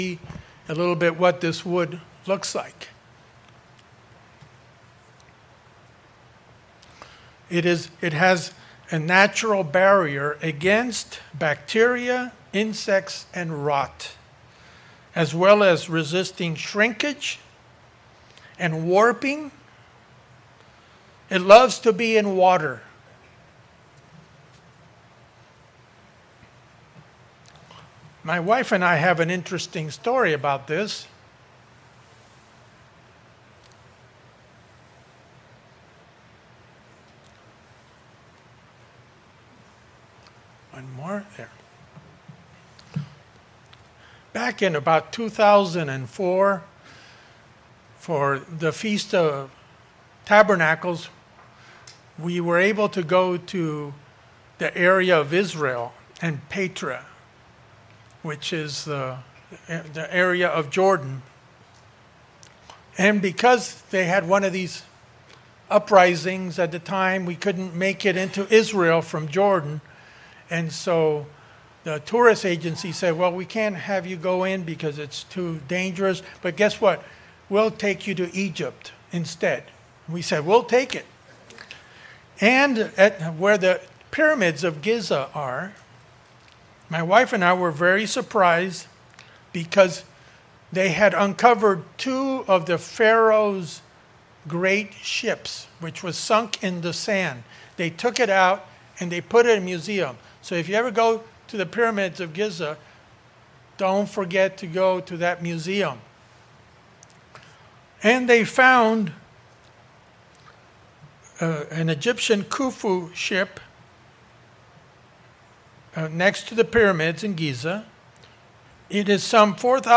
Noah's Ark Seminar